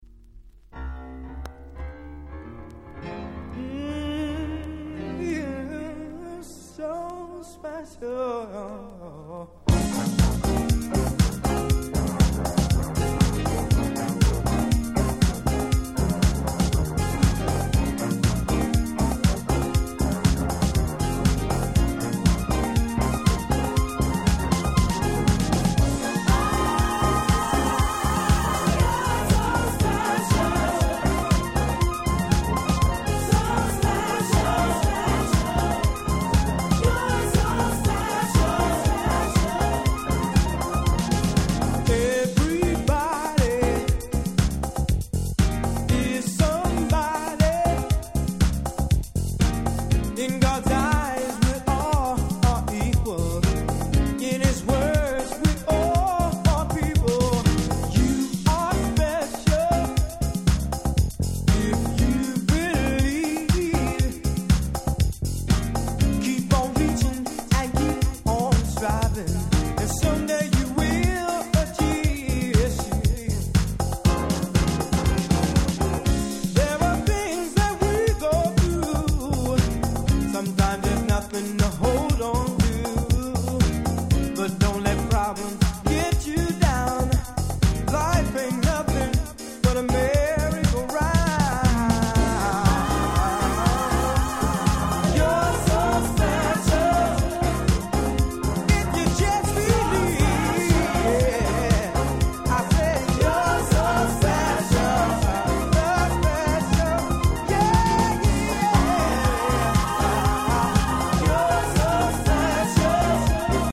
90' Nice Soul/R&B LP !!